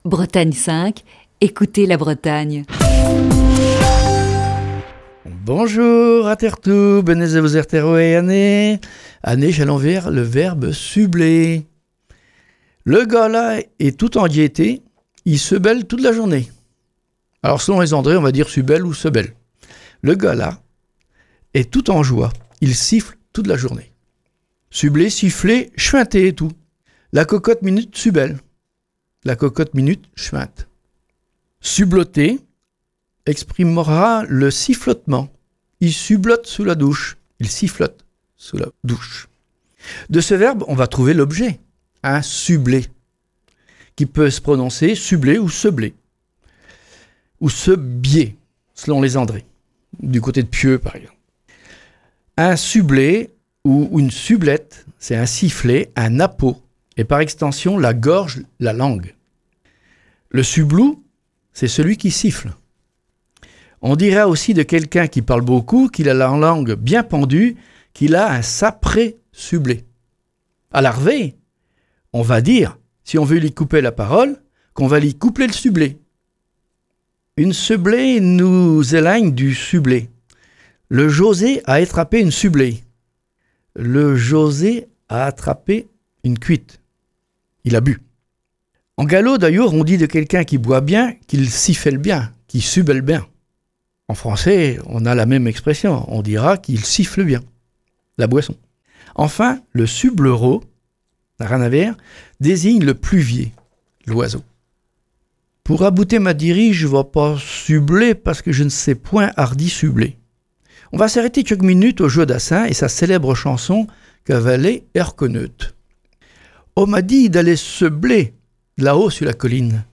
Chronique du 8 juillet 2020.
(Chronique diffusée le 16 octobre 2019).